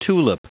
Prononciation du mot tulip en anglais (fichier audio)
Prononciation du mot : tulip